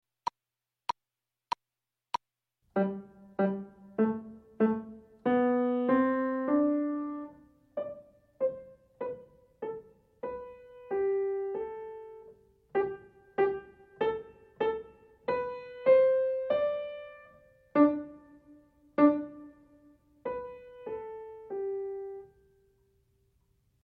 연습